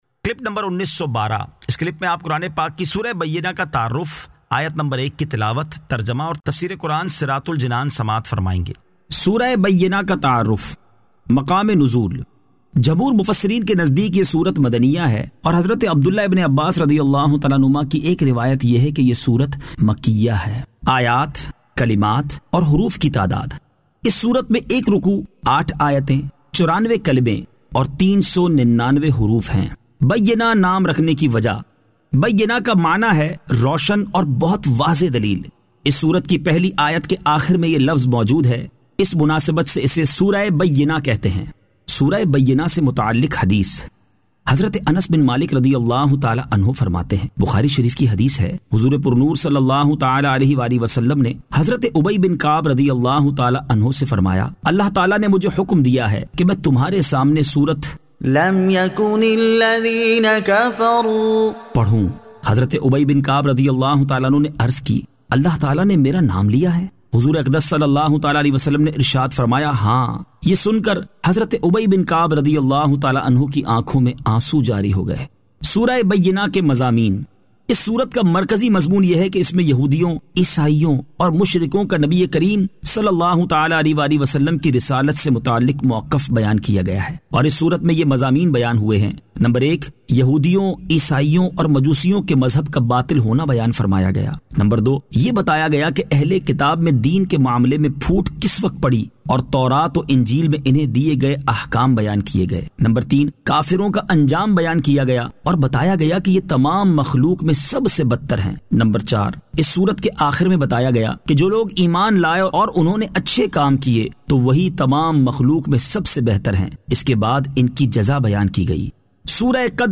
Surah Al-Bayyinah 01 To 01 Tilawat , Tarjama , Tafseer
2025 MP3 MP4 MP4 Share سُوَّرۃُ الْبَیِّنَۃ آیت 01 تا 01 تلاوت ، ترجمہ ، تفسیر ۔